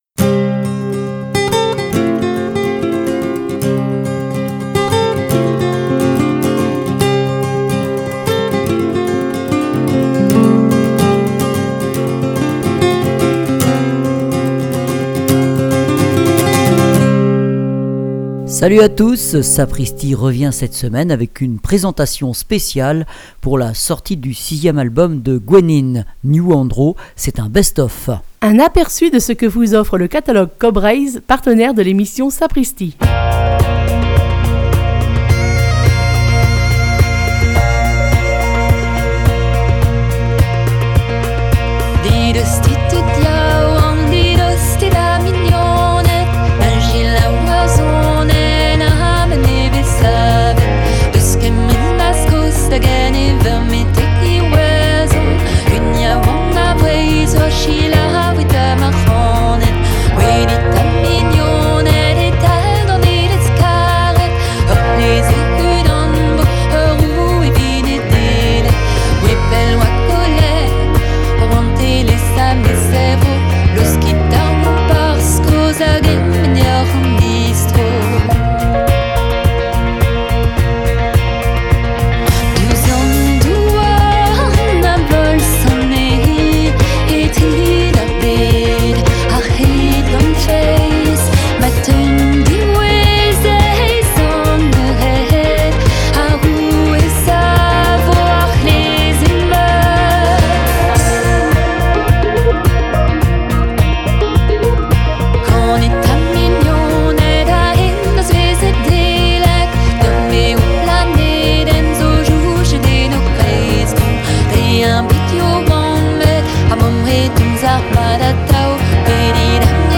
musiques traditionnelles et acoustiques